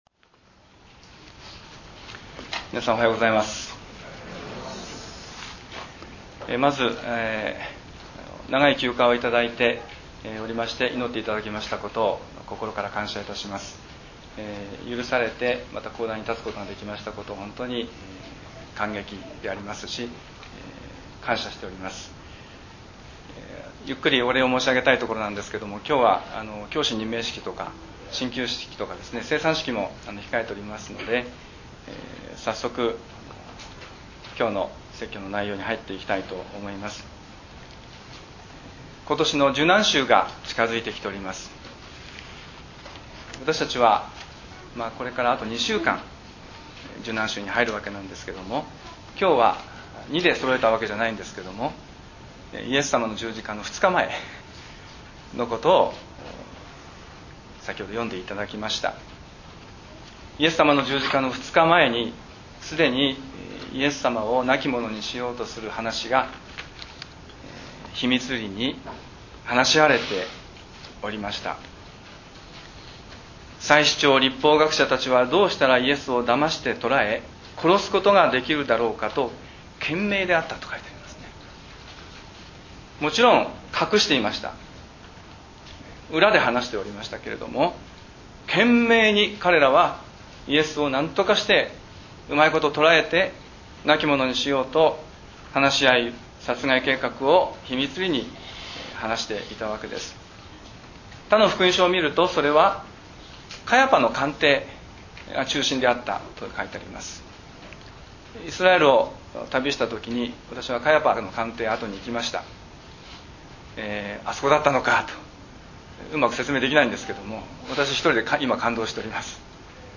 礼拝宣教録音－無駄でしょうか？